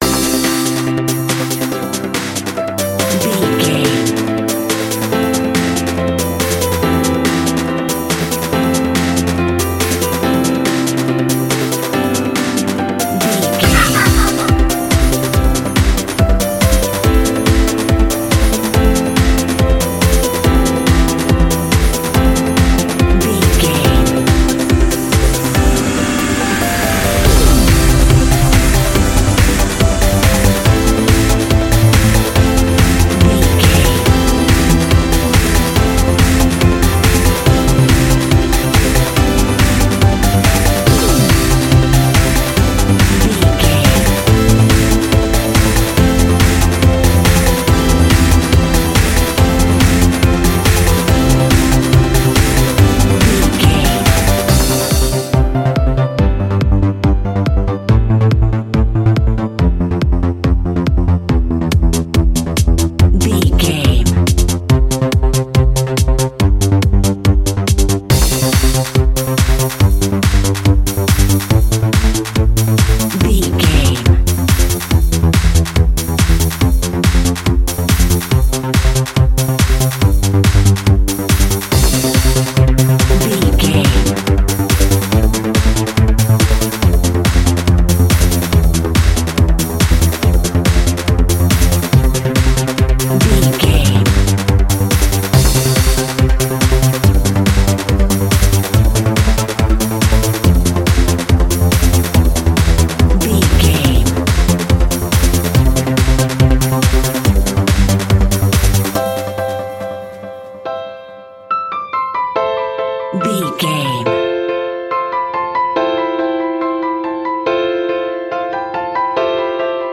Trance from a Festival.
Ionian/Major
Fast
uplifting
driving
energetic
drum machine
synthesiser
piano
strings
synth bass